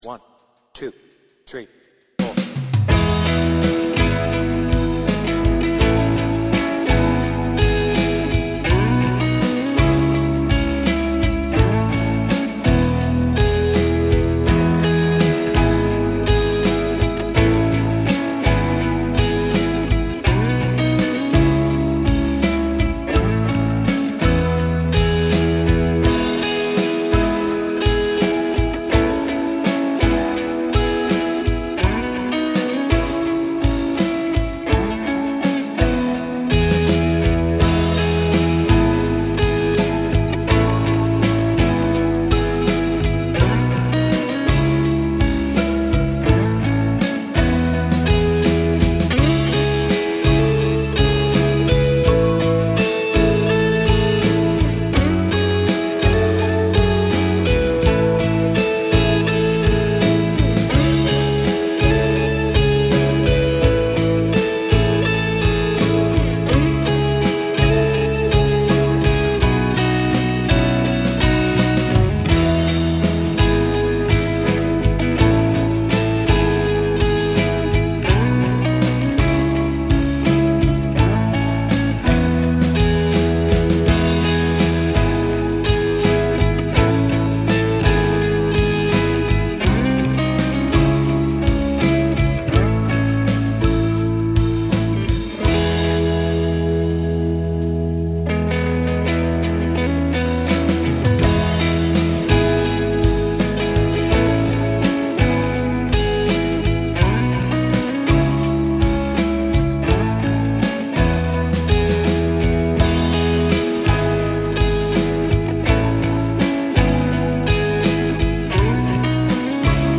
BPM : 83
Tuning : E
With Vocals